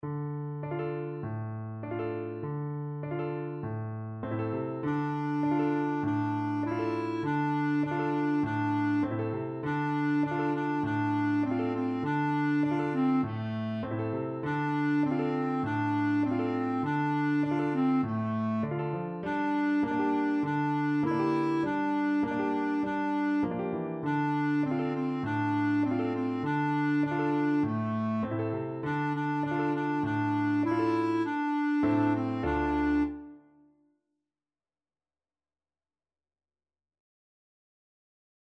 Clarinet
D minor (Sounding Pitch) E minor (Clarinet in Bb) (View more D minor Music for Clarinet )
4/4 (View more 4/4 Music)
Moderato
Traditional (View more Traditional Clarinet Music)
kagome_kagome_CL.mp3